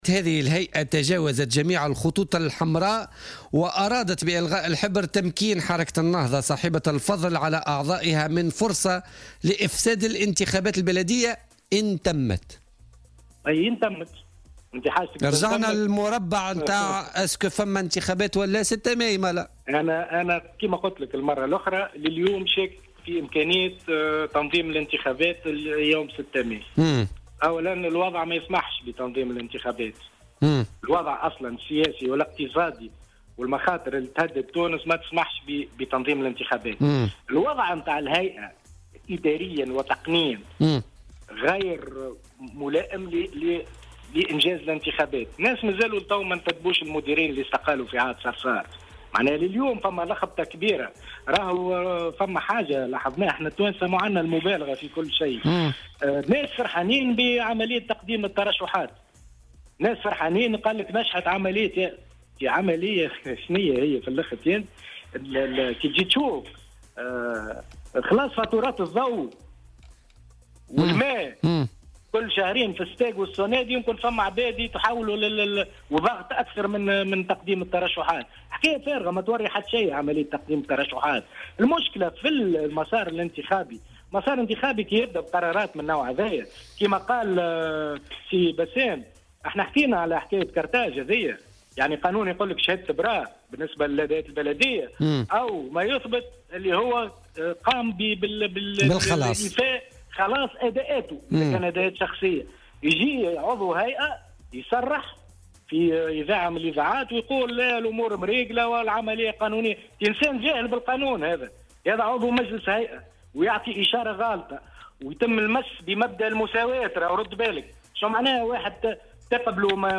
وقال خلال مشاركته في برنامج "بوليتيكا" على "الجوهرة أف أم" إنه يشك في امكانية تنظيم هذه الانتخابات يوم 6 ماي 2018.